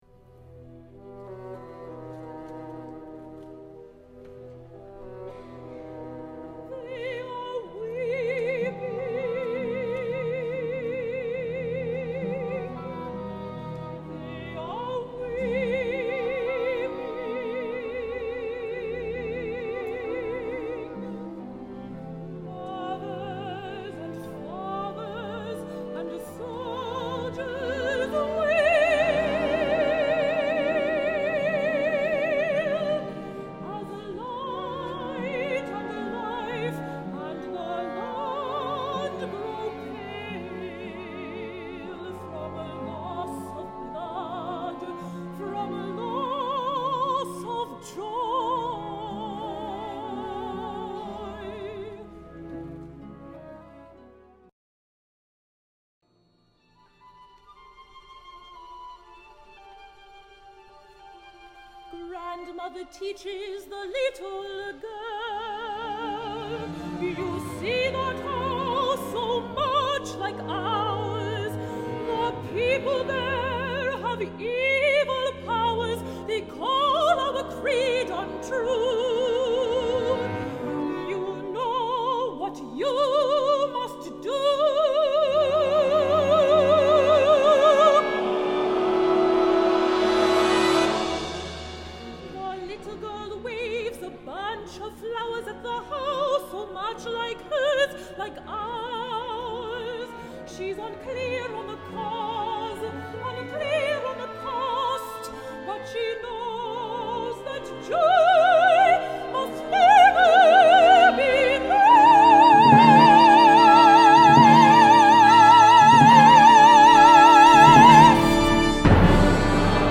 Metropolitan Church, Toronto